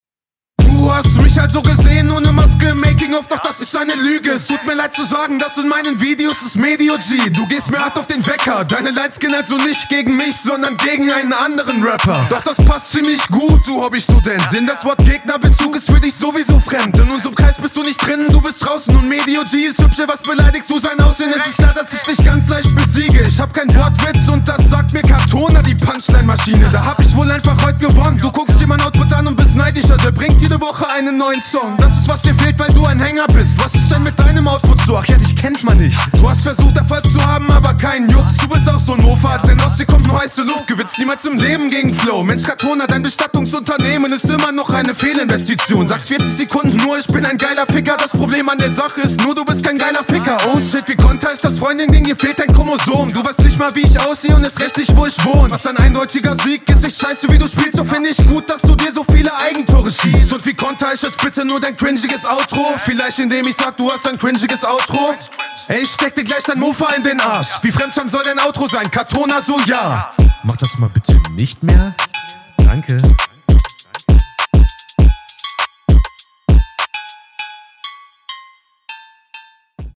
Abmische hier geiler und nicht so Monoton.